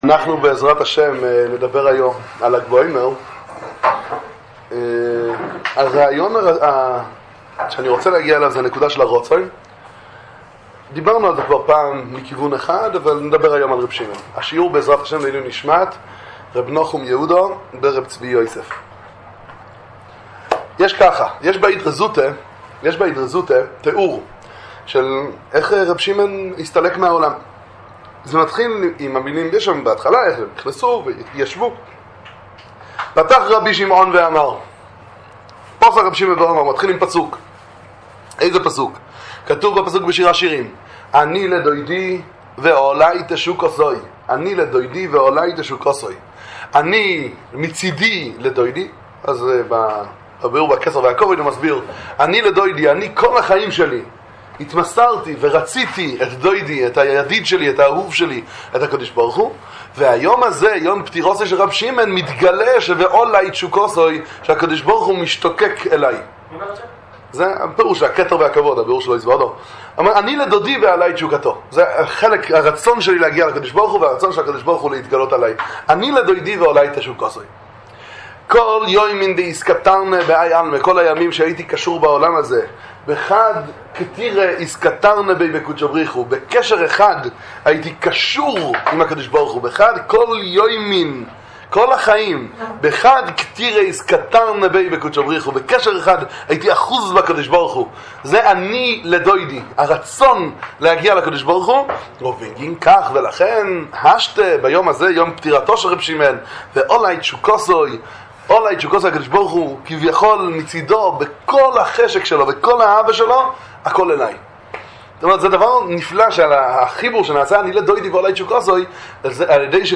שיעור על ענין רשב"י וימי ספירת העומר, דבר תורה על ל"ג בעומר, שיעורי תורה לימי הפסח והעומר